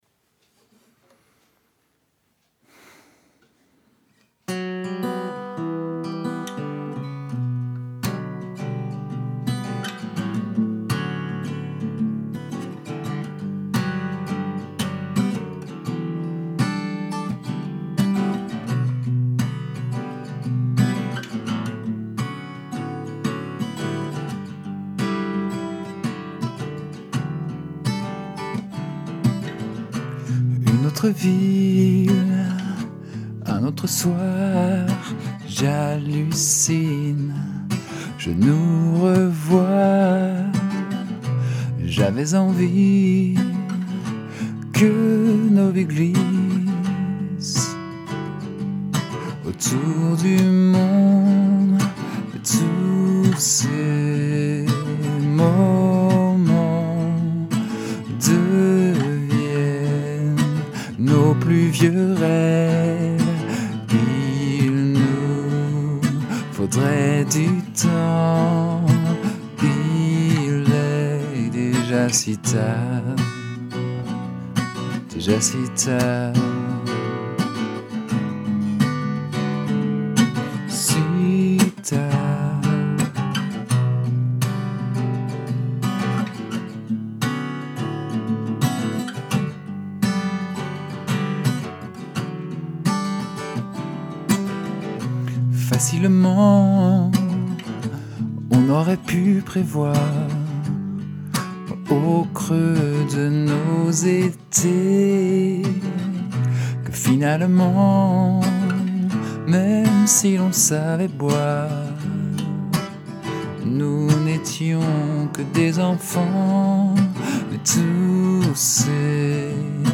auto-reprise de ma chanson "Si tard", autrement dit une interprétation différente de la version que vous connaissez peut-être.